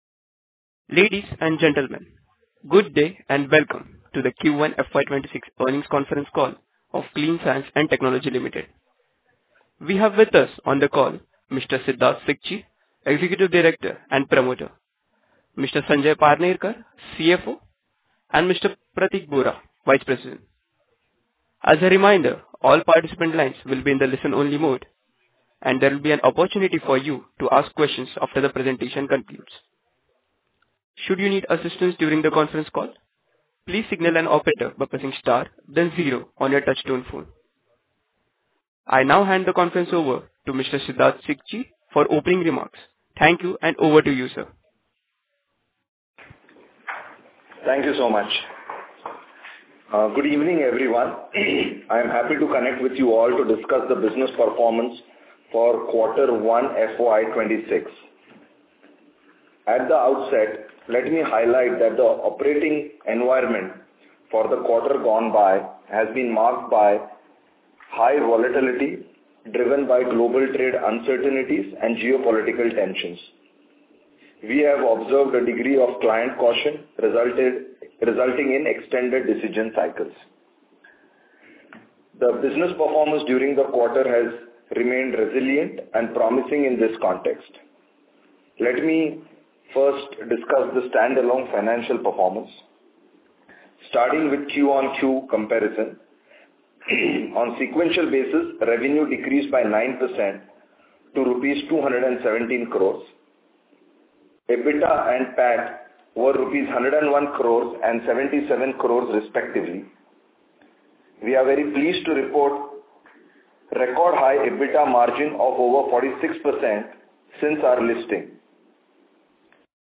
Recording-of-con-call-Q1FY26.mp3